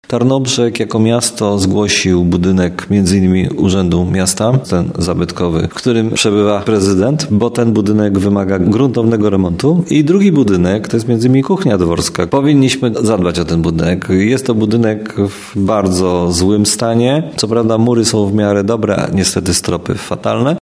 Do 17 marca został przedłużony nabór wniosków o dofinansowanie w ramach Rządowego Programu Odbudowy Zabytków. – Wnioski napływają – powiedział nam prezydent Tarnobrzega Dariusz Bożek.